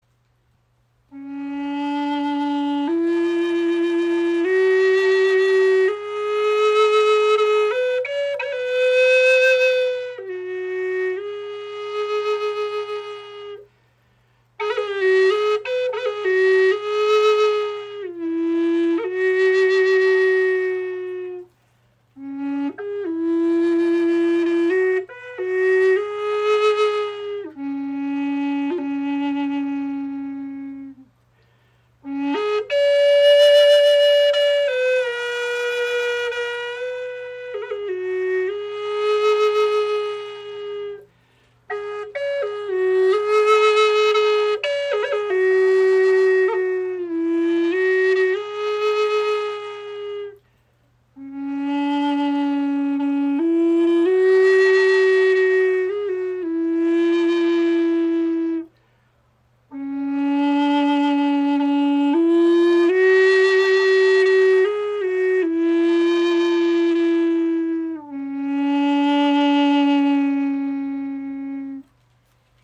Gebetsflöte in D – 432 Hz | Der Ruf des Raben | Teakholz 64 cm
• Icon Gestimmt in D (432 Hz) mit tiefem, heilsamem Klang
Diese tiefe Gebetsflöte in D, gestimmt auf die harmonische Frequenz von 432 Hz, ist dem Spirit des Raben gewidmet.